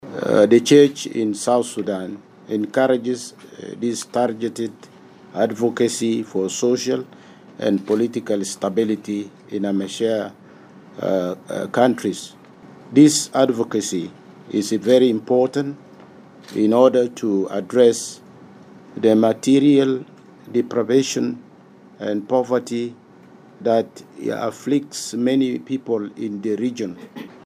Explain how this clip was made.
The Catholic prelates were speaking during the opening of a three-day meeting at the Sudan and South Sudan Bishops’ Conference in Juba.